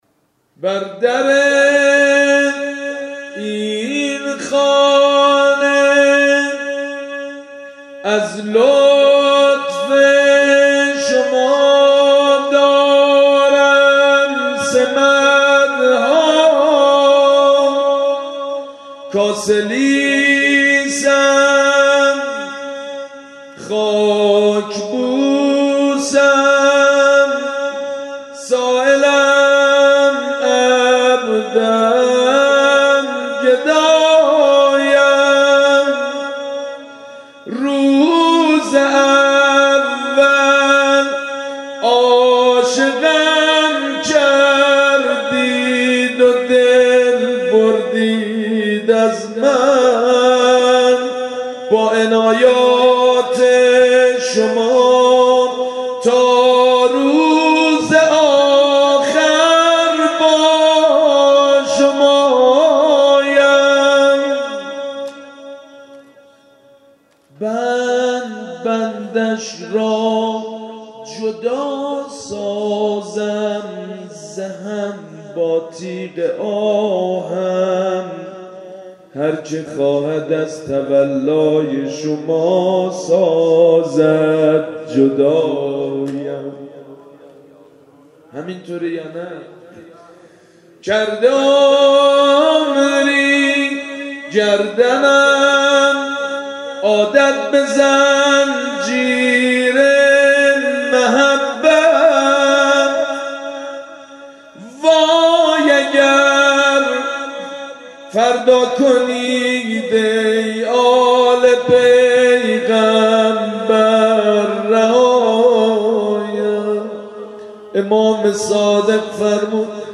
صوت مداحی سید مجید بنی فاطمه در حسینیه اوین
صوت مداحی سید مجید بنی فاطمه در حسینیه اوین کد خبر
روضه سید مجید بنی فاطمه دانلود سینه زنی دانلود لینک کپی شد گزارش خطا پسندها 0 اشتراک گذاری فیسبوک سروش واتس‌اپ لینکدین توییتر تلگرام اشتراک گذاری فیسبوک سروش واتس‌اپ لینکدین توییتر تلگرام